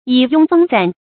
蟻擁蜂攢 注音： ㄧˇ ㄩㄥ ㄈㄥ ㄘㄨㄢˊ 讀音讀法： 意思解釋： 比喻集結者眾多。